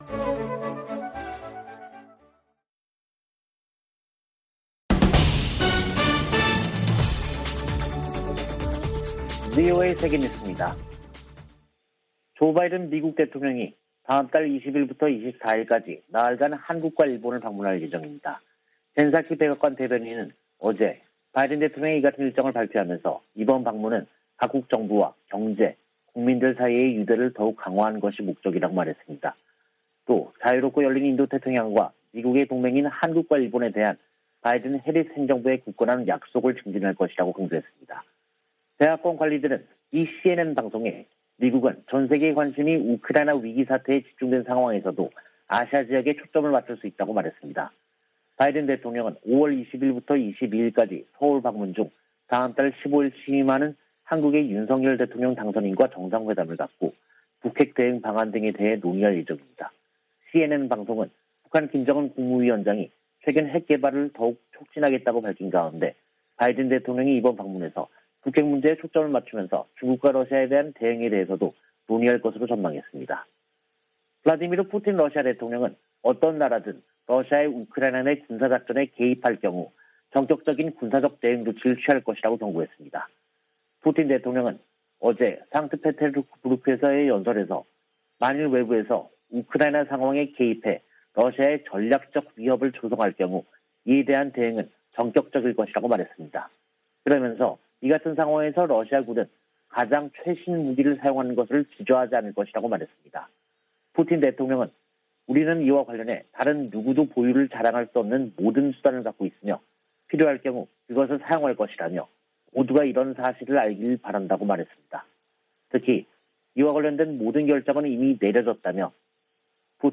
VOA 한국어 간판 뉴스 프로그램 '뉴스 투데이', 2022년 4월 28일 3부 방송입니다. 조 바이든 대통령이 다음 달 20일부터 24일까지 한국과 일본을 방문합니다. 한국의 윤석열 대통령 당선인 측은 바이든 대통령 방한을 환영하면서 포괄적 전략동맹이 강화되는 계기가 될 것으로 기대했습니다. 미 하원 청문회에서 '파이브 아이즈(Five Eyes)' 정보 동맹을 한·일 등으로 확대하는 문제가 거론됐습니다.